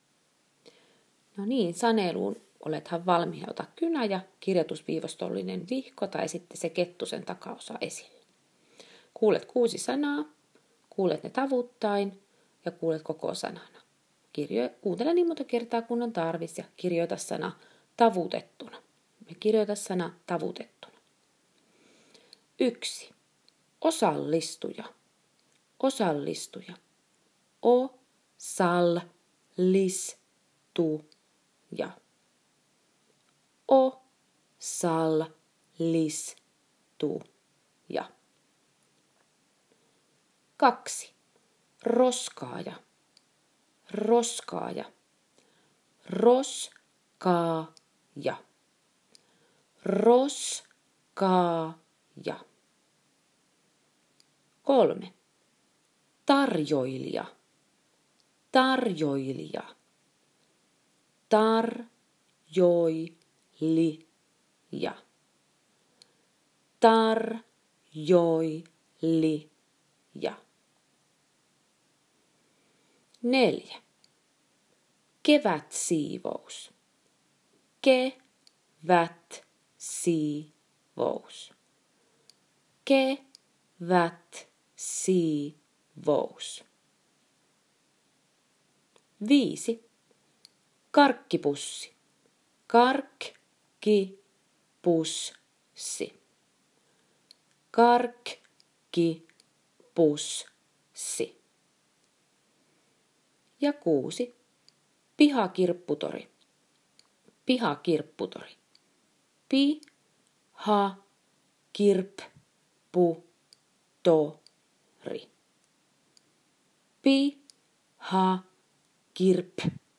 • Tee myös open sanelu